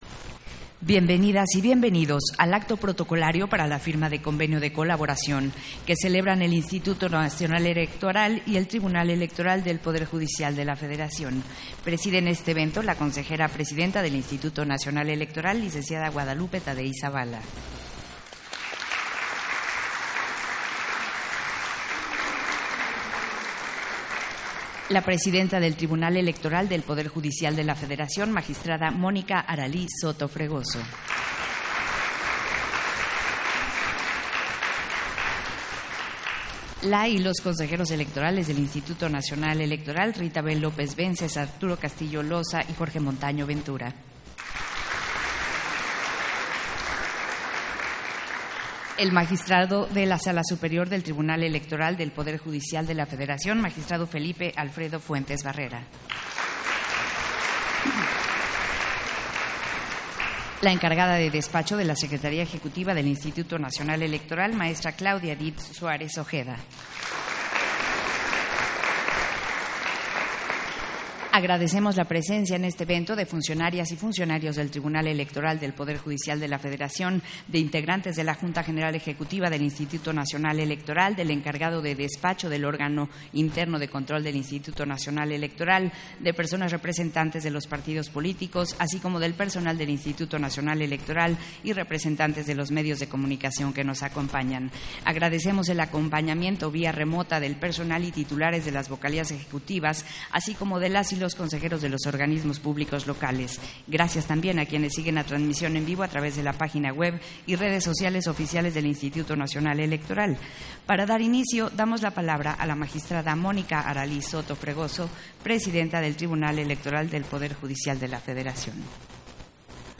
110324_AUDIO_FIRMA-DE-CONVENIOS-MARCO-Y-ESPECÍFICO-DE-COLABORACIÓN-INE-TEPJF